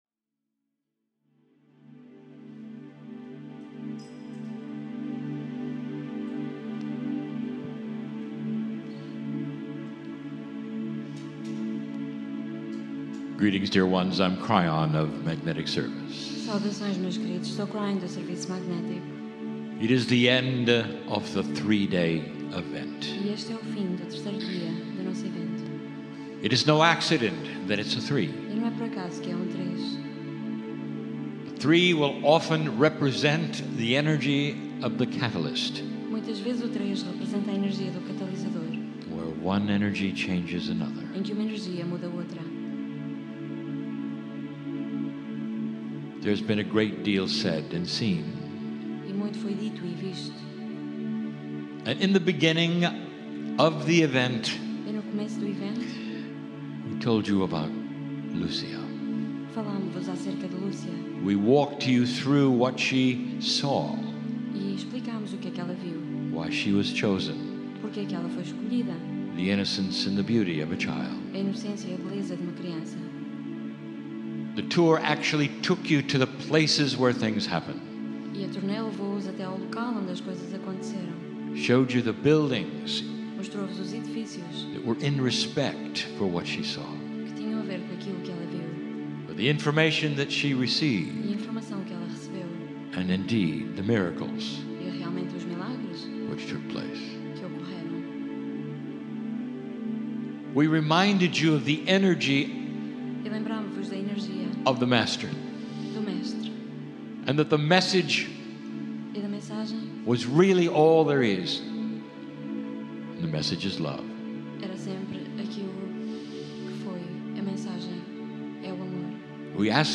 END MEETING - FATIMA TOUR - PORTUGAL
KRYON CHANNELLING Porto - End of Fatima Channeling 12:43 minutes 15.4 megabytes Porto-1.mp3